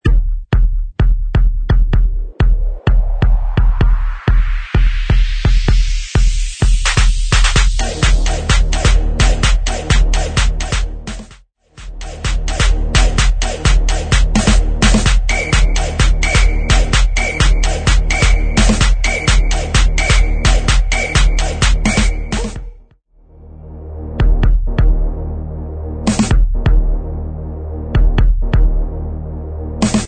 128 BPM
Gqom